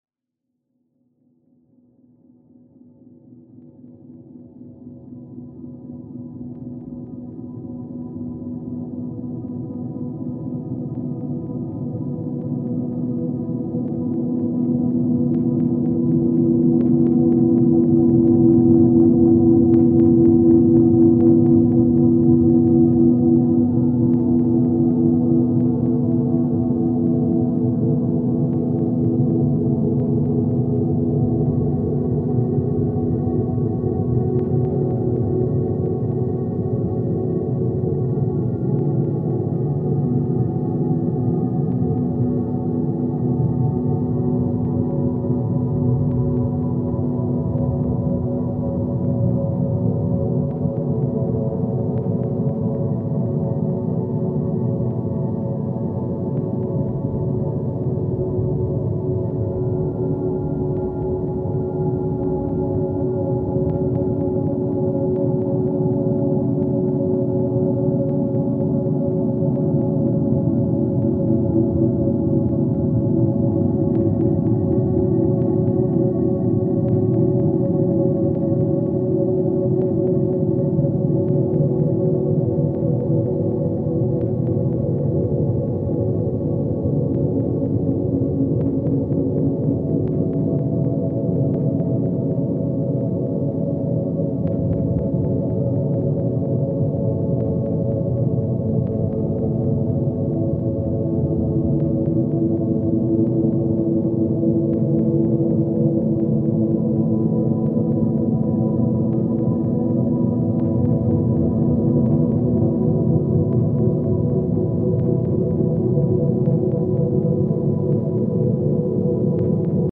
a very meditative and deeply atmospheric work
haunting minimal ambient electronics